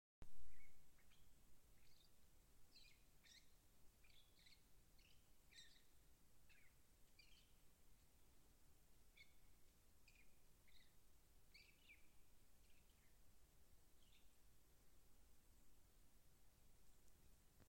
Lielā čakste, Lanius excubitor
Administratīvā teritorijaMālpils novads
Piezīmes/Sēdēja koka galotnē un dziedāja tā kā pavasarī.